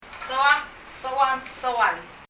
trash box ゴミ箱 » like 好きだ ng soak ・・・ [ŋ sɔə(k)] 英） like 日） 好きだ Leave a Reply 返信をキャンセルする。